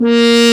Index of /90_sSampleCDs/Roland LCDP12 Solo Brass/BRS_French Horn/BRS_Mute-Stopped